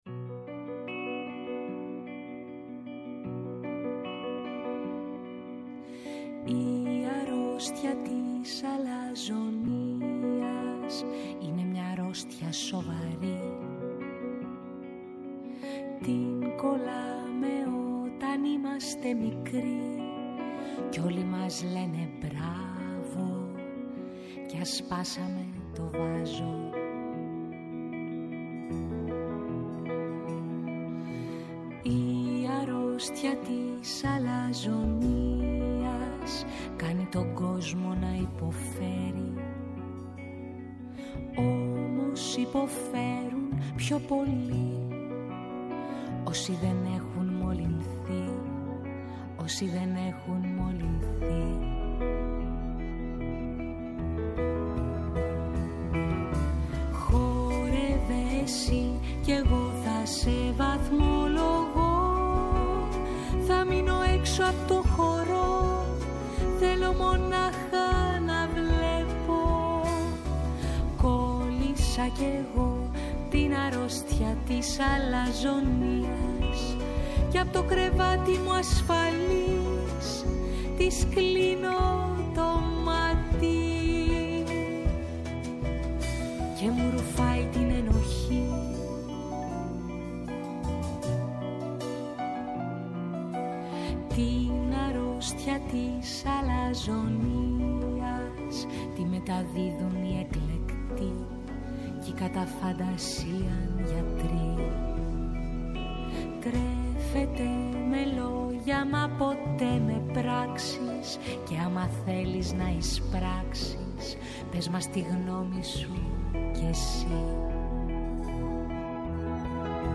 -ο Θεόδωρος Ρουσόπουλος, Βουλευτής Βορείου Τομέα Αθηνών Νέας Δημοκρατίας
Κάθε Παρασκευή 11:00-12:00 , στο Πρώτο Πρόγραμμα της Ελληνικής Ραδιοφωνίας. «Με τούτα και μ εκείνα», τελειώνει η εβδομάδα τις καθημερινές.